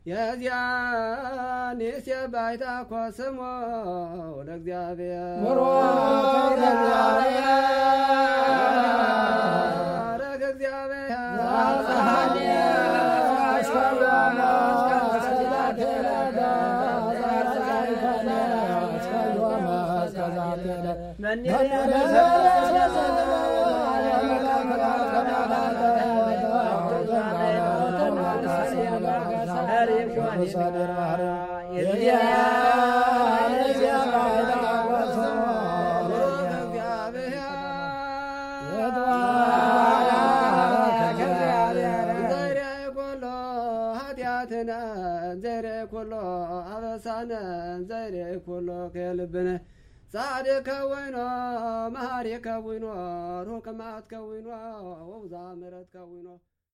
The Ethiopian liturgy is composed of spoken and sung prayers, exclusively performed by men, in Gé’ez language.
The atmosphere that comes out reflects of an African originality.
14_prieres_pour_la_ceremonie_du_mariage.mp3